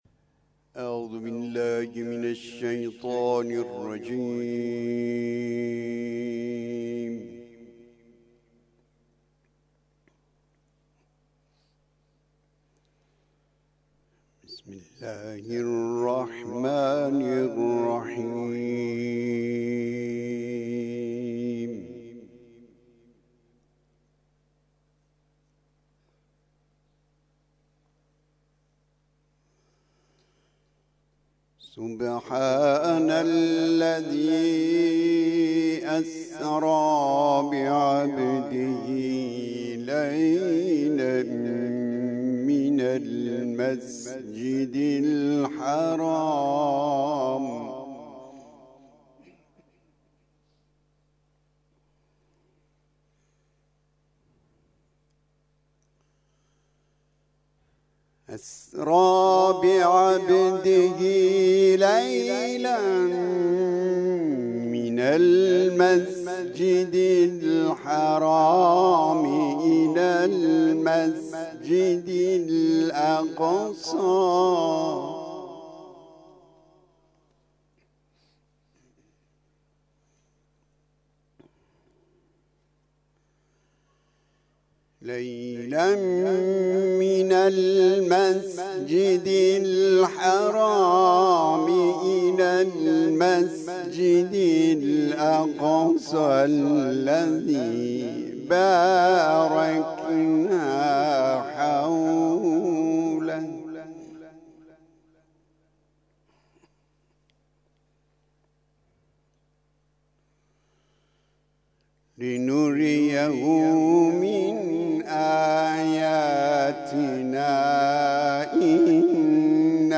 تلاوت ظهر - سوره اسراء آیات (۱ تا ۹)